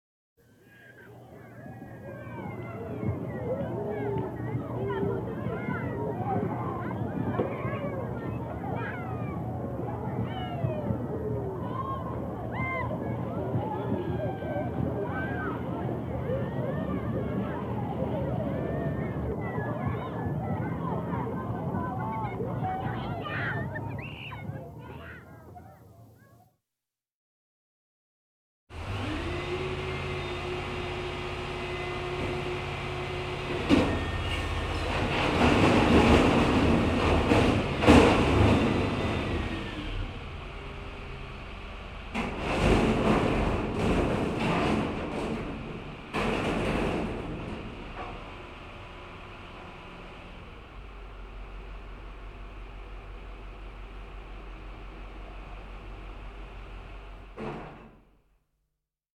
Großstadtgeräusche
kinder auf dem spielplatz - 2. müllwagen beim abladen.mp3
kinder_auf_dem_spielplatz_-_2._müllwagen_beim_abladen.mp3